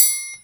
percussion 16.wav